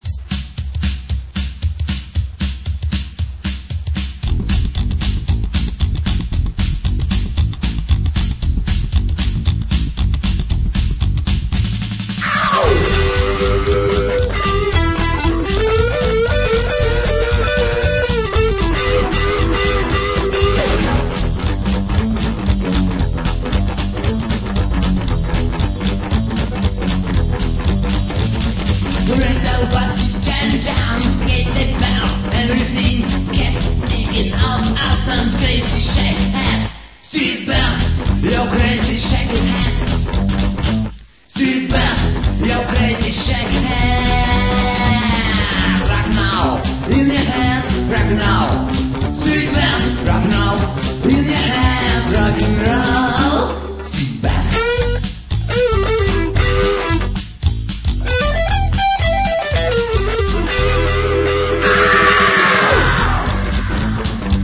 Сумашедший рок-н-ролл уличных банд...